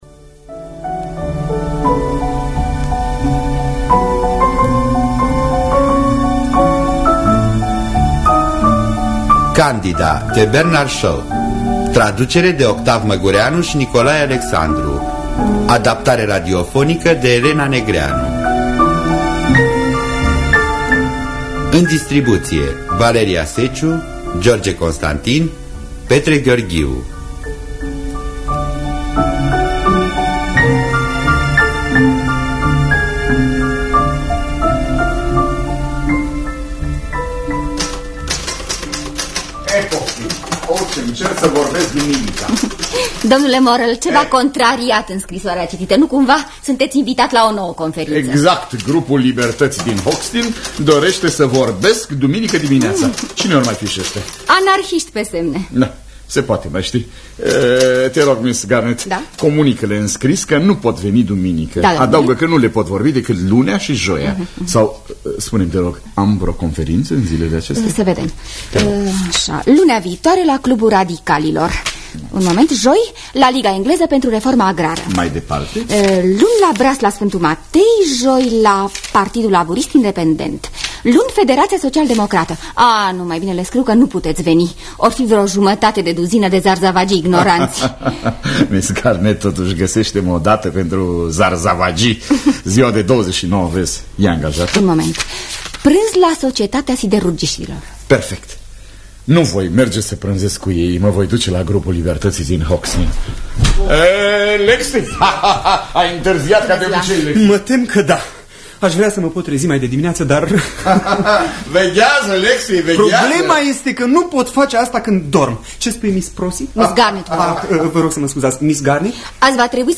Adaptarea radiofonică şi regia artistică